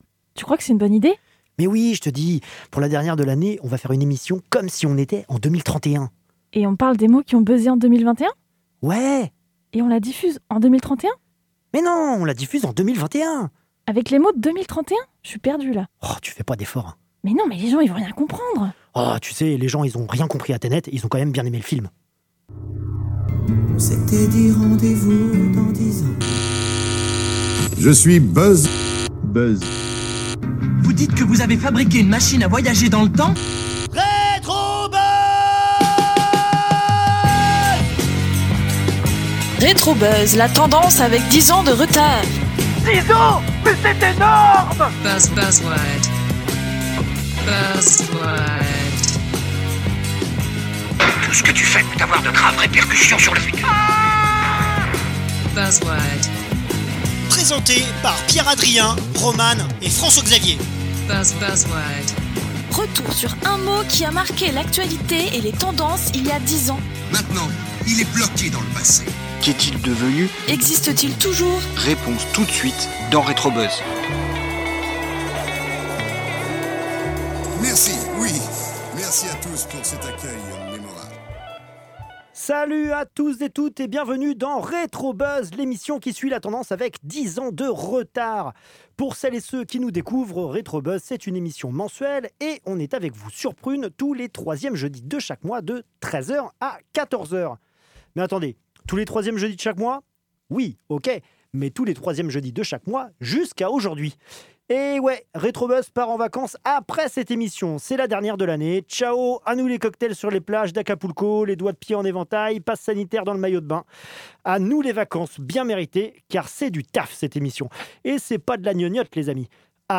Au programme de cette émission spéciale : des micro trottoirs, des chroniques et des décryptages.